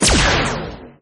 plaser-turret-fire.ogg